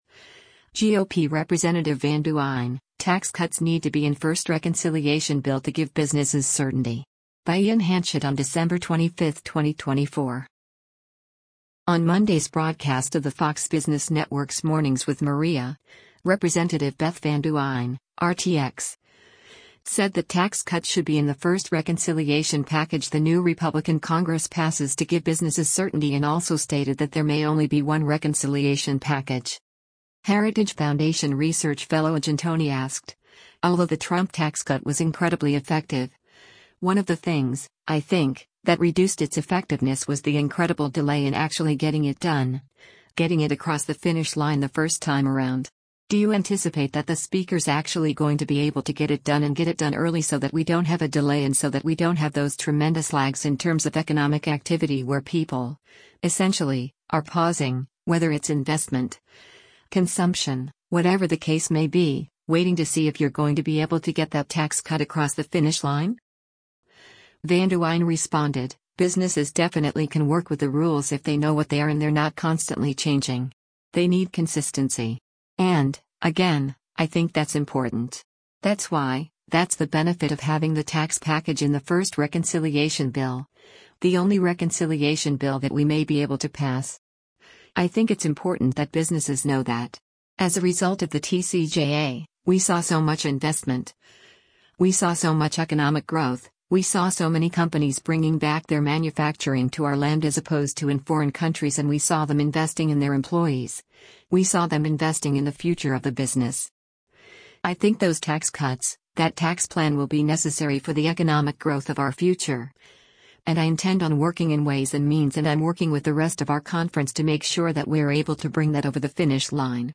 On Monday’s broadcast of the Fox Business Network’s “Mornings with Maria,” Rep. Beth Van Duyne (R-TX) said that tax cuts should be in the first reconciliation package the new Republican Congress passes to give businesses certainty and also stated that there may only be one reconciliation package.